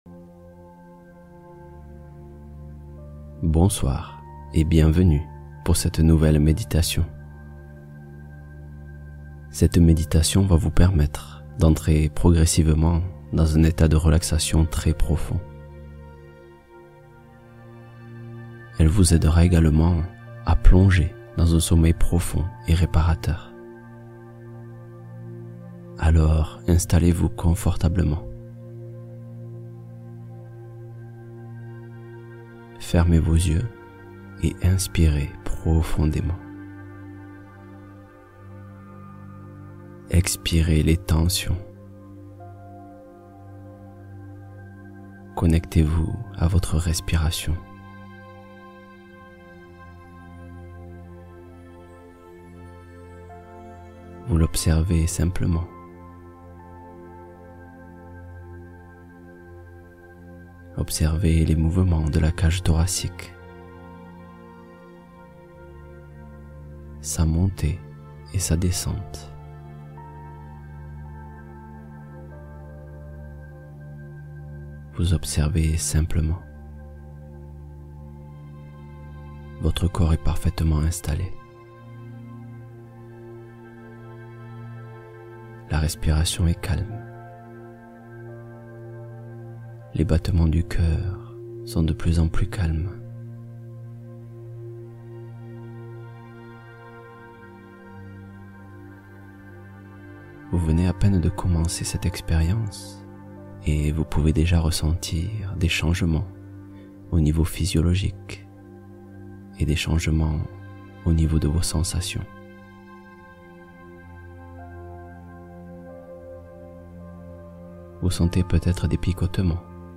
Confiance en soi — Méditation guidée pour soutenir l’évolution intérieure